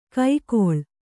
♪ kai koḷ